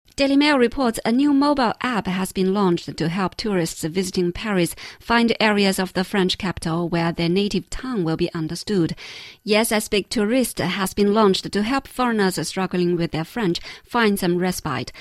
记得带上新APP的听力文件下载,《双语趣听精彩世界》栏目通过讲述中外有趣的故事，来从不同的角度看中国、看世界，是了解大千世界的极好材料。中英双语的音频，能够帮助提高英语学习者的英语听说水平，中外主持人的地道发音，是可供模仿的最好的英语学习材料,可以帮助英语学习者在轻松娱乐的氛围中逐渐提高英语学习水平。